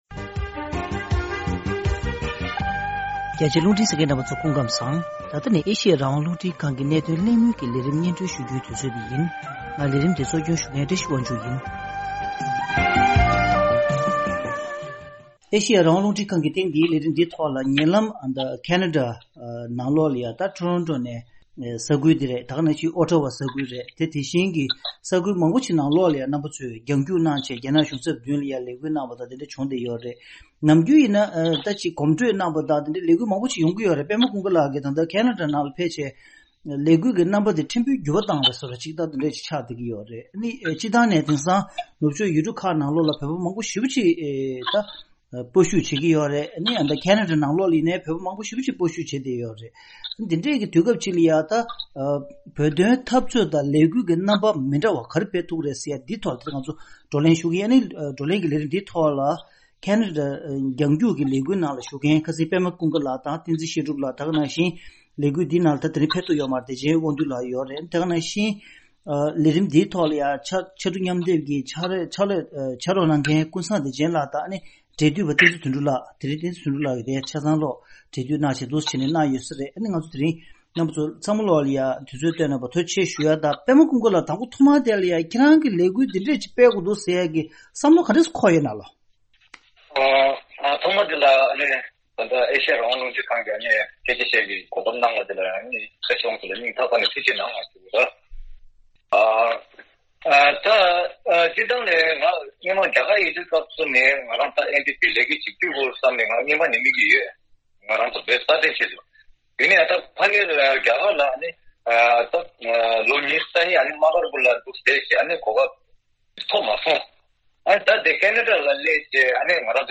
གནད་དོན་གླེང་མོལ་གྱི་ལས་རིམ